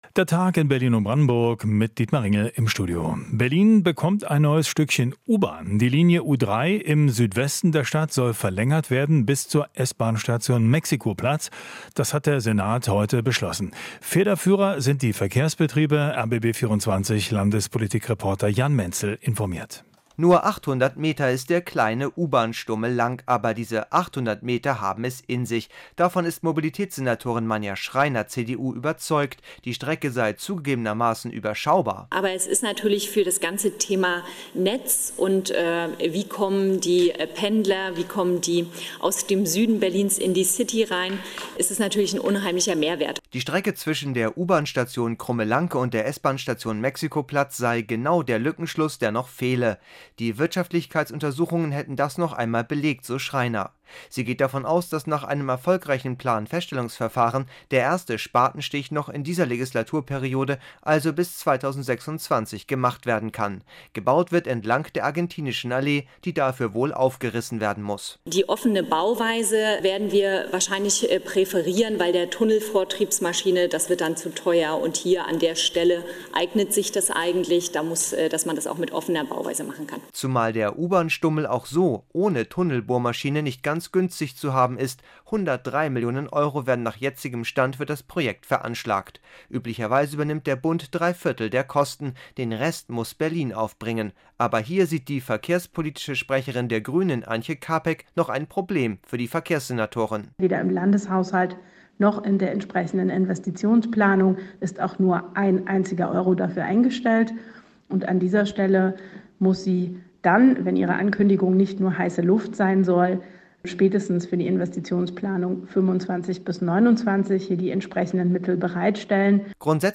Alles was wichtig ist in der Hauptstadtregion - in Interviews, Berichten und Reportagen.